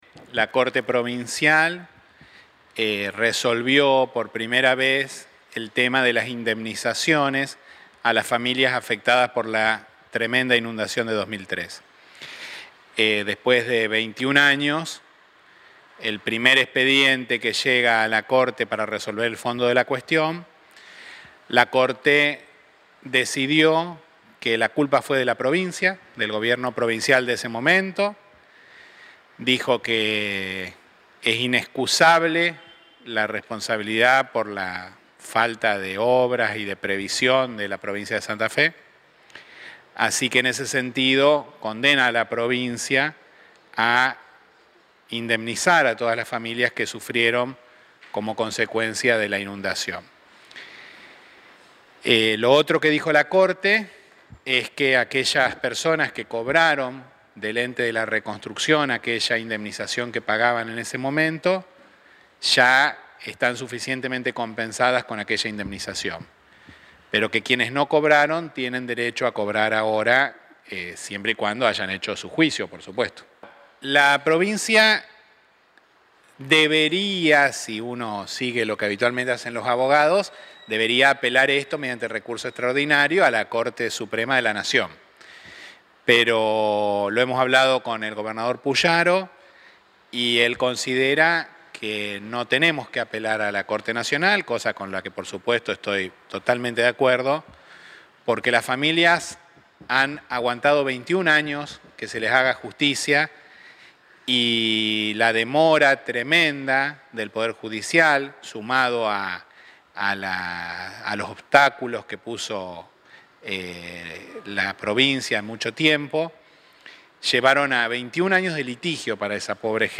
ESCUCHA LA PALABRA DEL FISCAL DE ESTADO DOMINGO RONDINA: